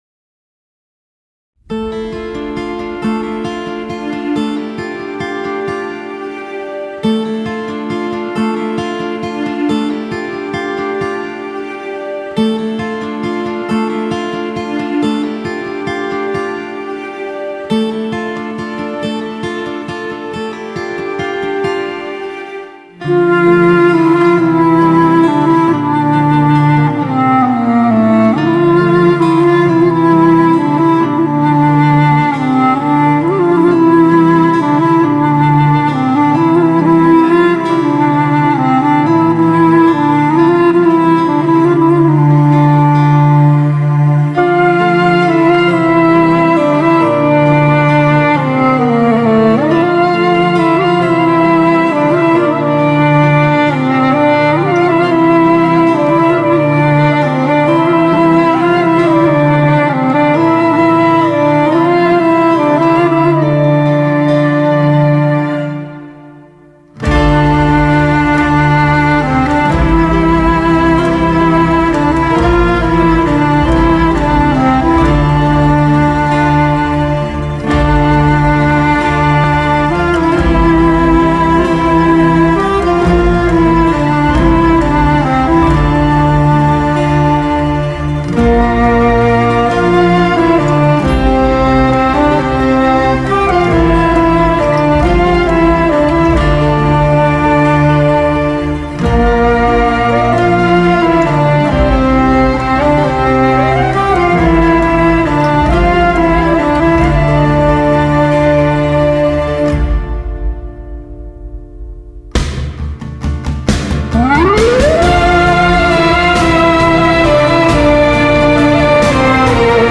tema dizi müziği, duygusal hüzünlü rahatlatıcı fon müzik.